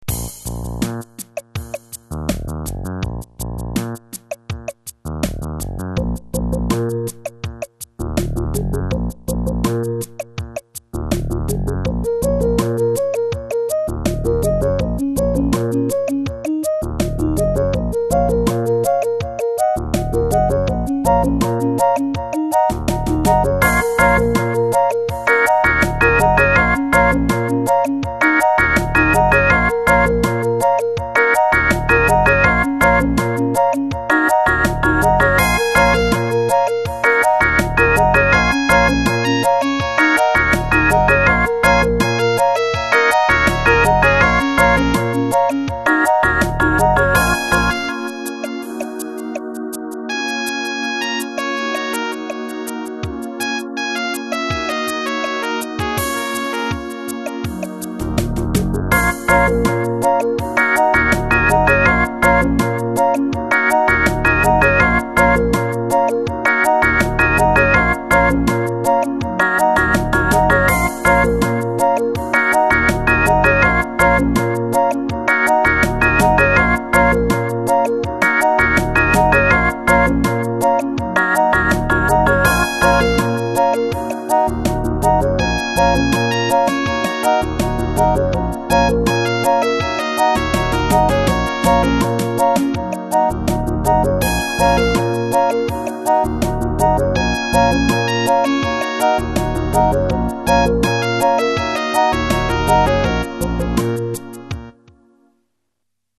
• 80 kbps, 22050 Hz
• Music has an ending (Doesn't loop)
Created from the MIDI using Timidity